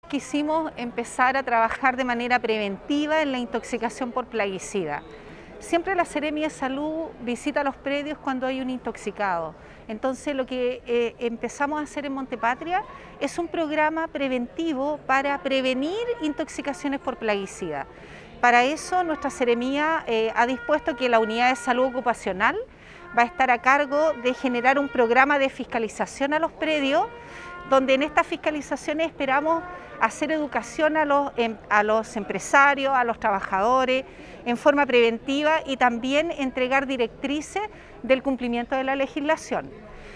AUDIO : Seremi de Salud Paola Salas